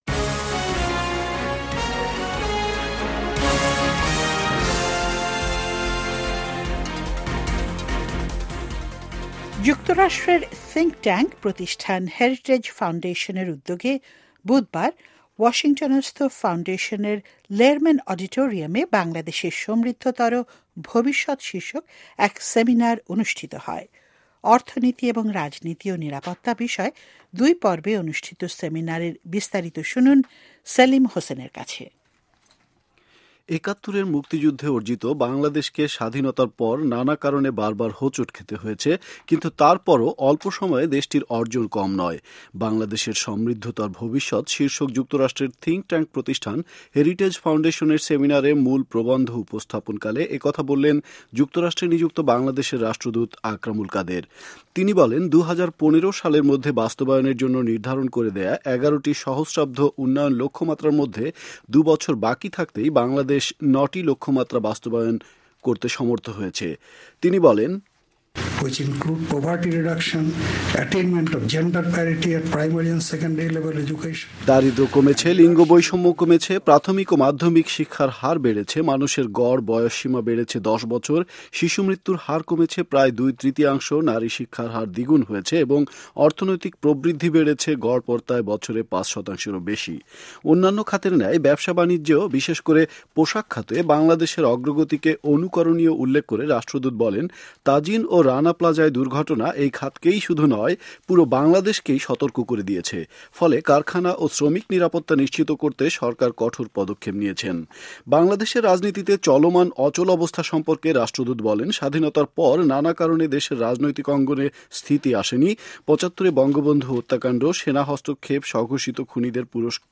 হেরিটেজ ফাউনডেশানের সেমিনার বিষয়ে রিপোর্ট